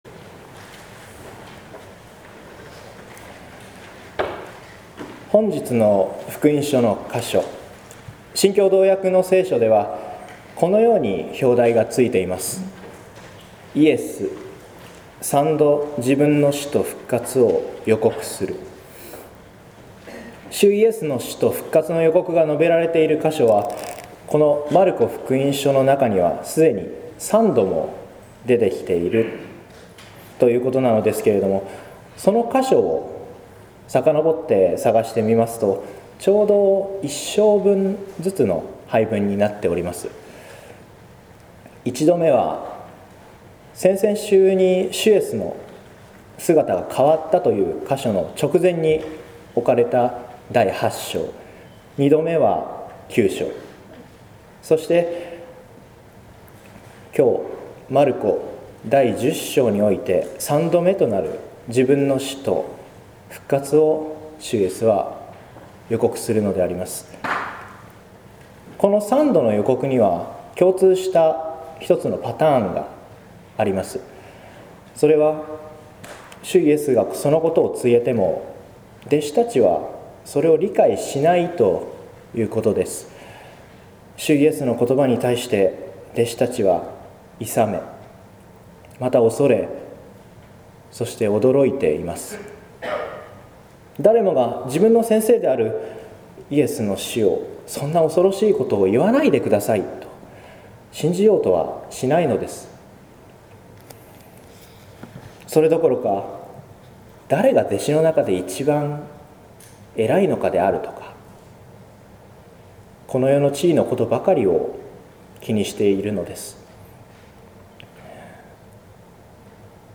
説教「進んでその身を僕とす」（音声版） | 日本福音ルーテル市ヶ谷教会
四旬節第２主日（2015年3月1日）